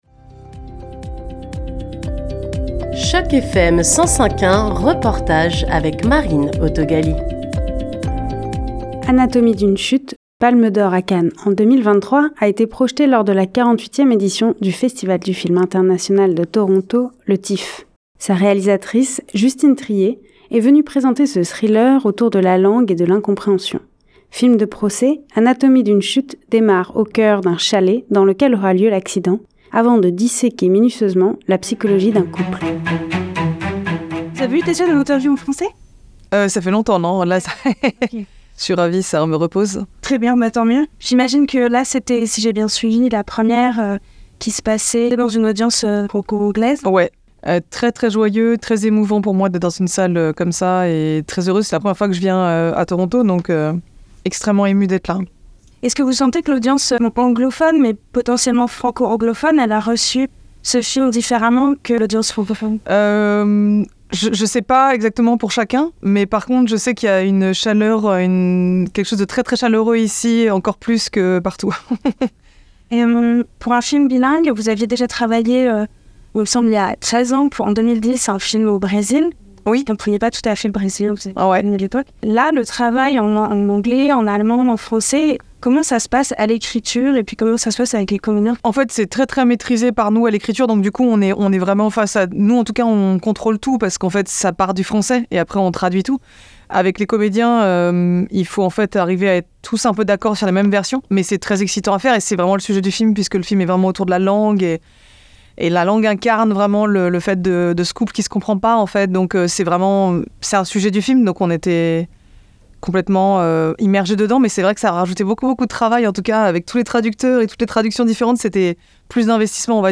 ITW-Justine-Triet_GOOD.mp3